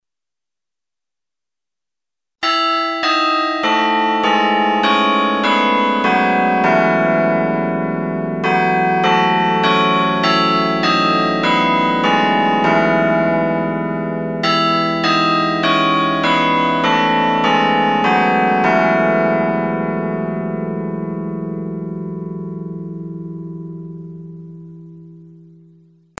The Style E1002 Digital Chime system provides amplified audio output of a variety of digital chimes through a digital sound card and amplifier.
Chime Samples